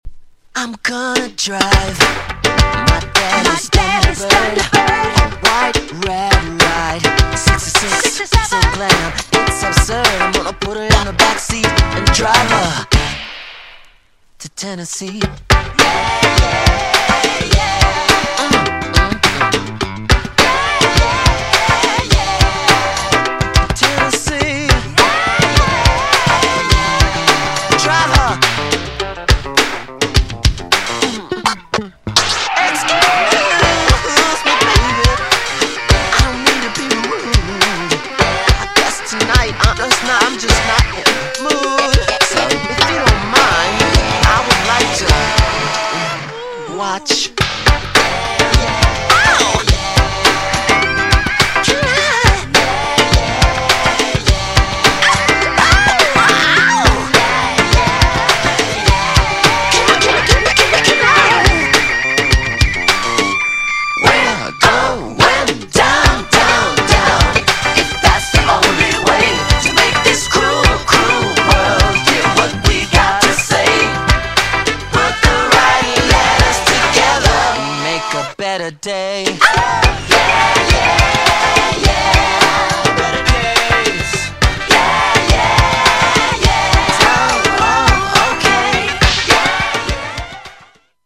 GENRE Dance Classic
BPM 121〜125BPM